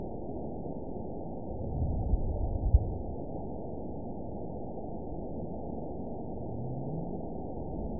event 917162 date 03/22/23 time 14:37:54 GMT (2 years, 1 month ago) score 9.59 location TSS-AB01 detected by nrw target species NRW annotations +NRW Spectrogram: Frequency (kHz) vs. Time (s) audio not available .wav